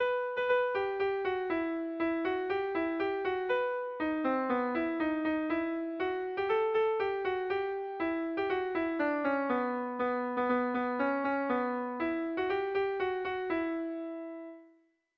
Irrizkoa
Seiko berdina, 5 puntuz eta 7 silabaz (hg) / Sei puntuko berdina (ip)
ABDEF